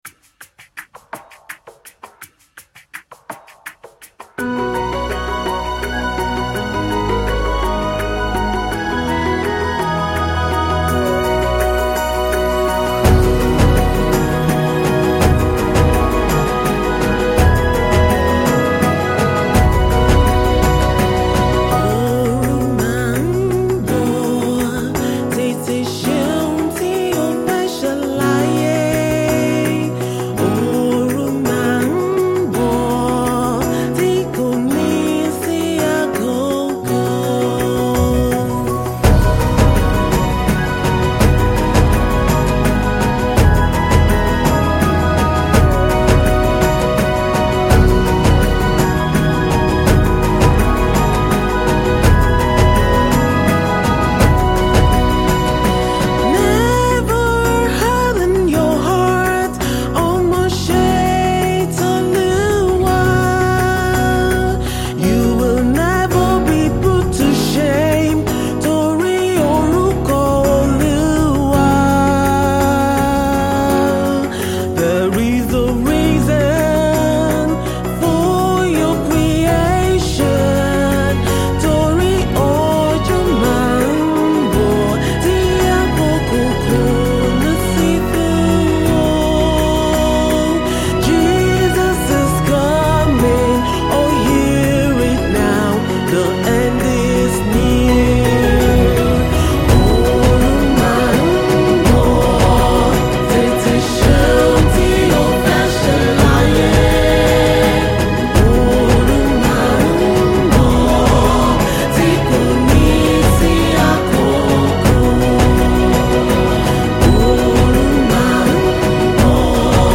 Nigerian gospel minister